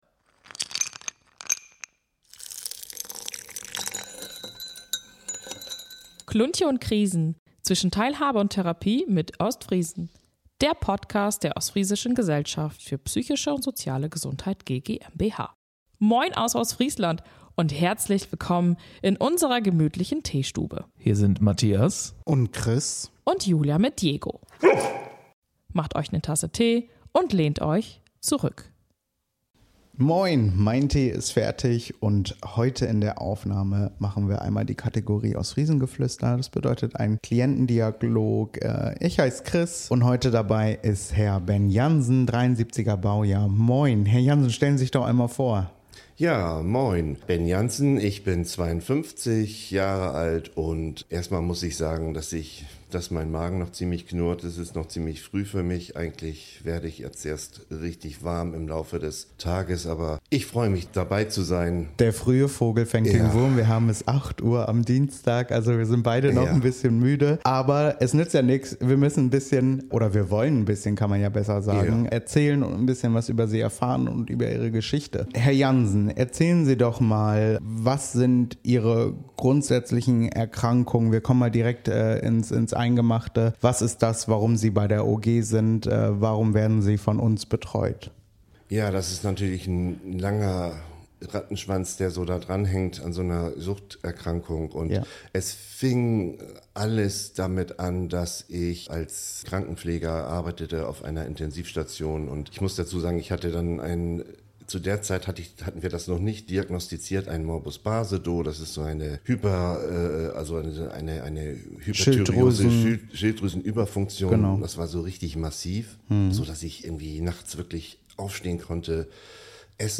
Es ist eine Geschichte von Stolpern und Kämpfen, aber auch von Erkenntnis, Mut und dem Wunsch nach Veränderung. Ein offenes Gespräch, das berührt, Hoffnung macht und zeigt: Man ist mit solchen Wegen nicht allein.